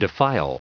Prononciation du mot defile en anglais (fichier audio)
Prononciation du mot : defile